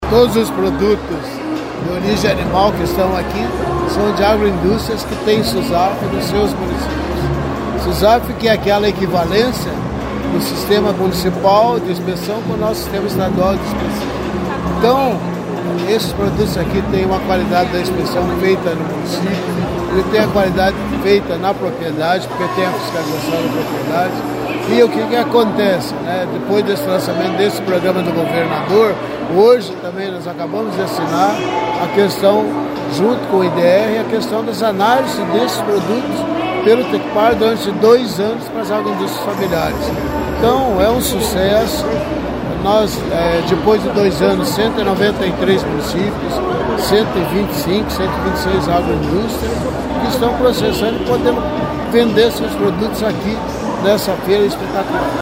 Sonora do diretor-presidente da Adapar, Otamir Martins, sobre o Susaf e as agroindústrias participantes da Feira Sabores do Paraná